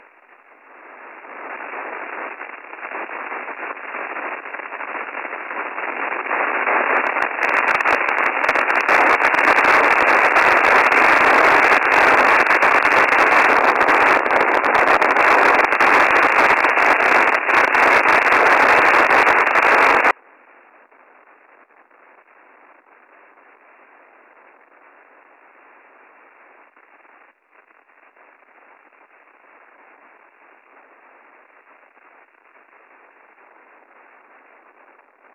File:Noise 14030kHz 22Mar18 1100gmt.mp3 - Signal Identification Wiki
A wideband noise centred around 14030kHz but there 24/7. Can also be heard every 400kHz either side and stretching down into medieum Wave and up towards 30MHz.